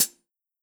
ClosedHH Groovin 1.wav